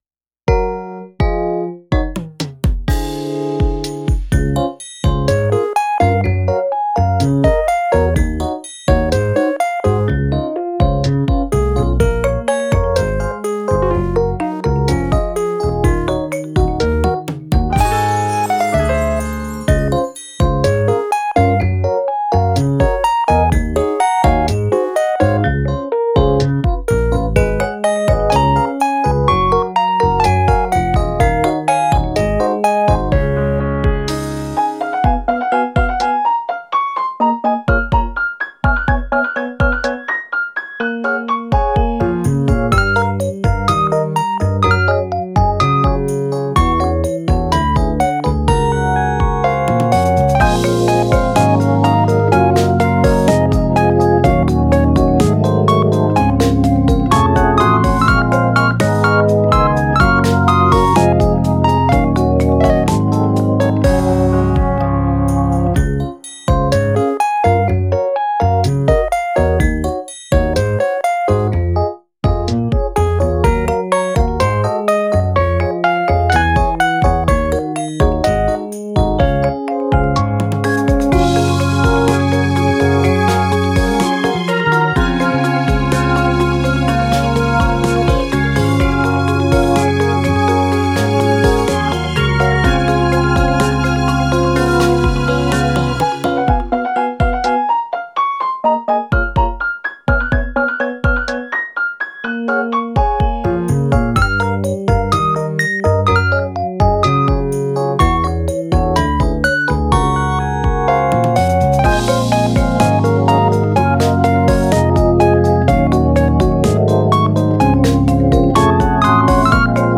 ループ用音源（BPM=125）